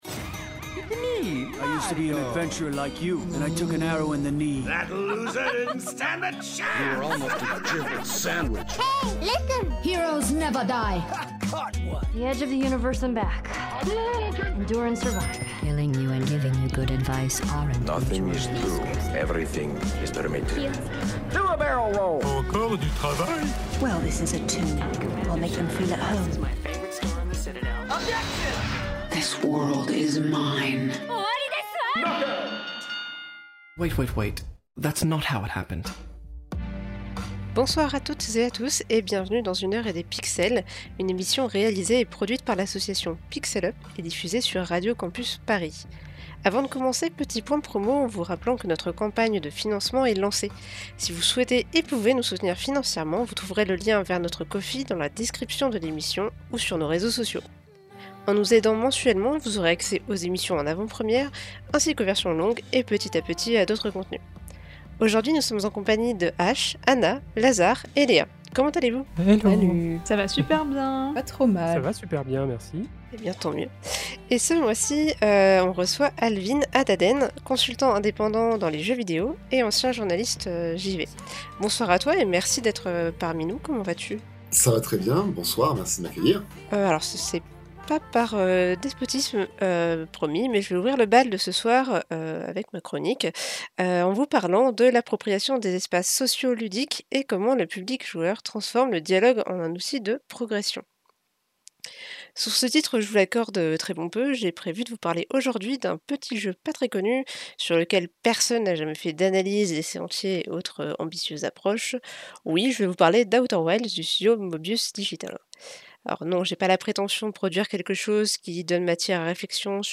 Magazine Culture